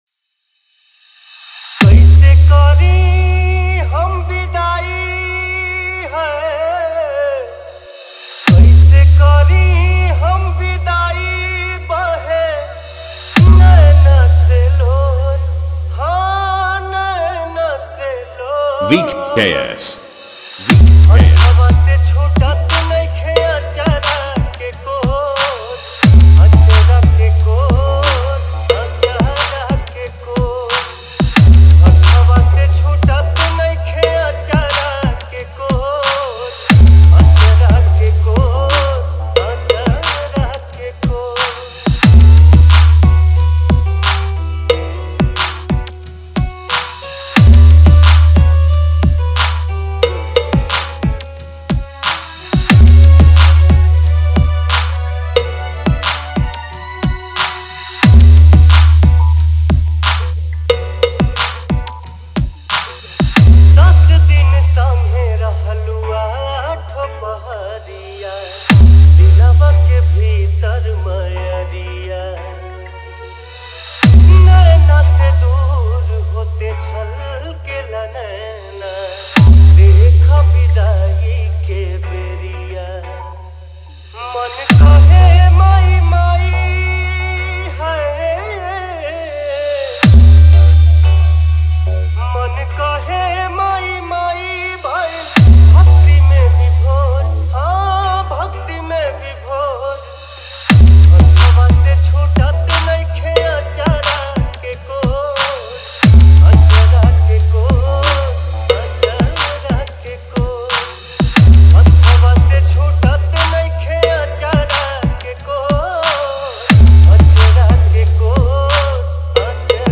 Durga Puja Visarjan Songs Dj Remix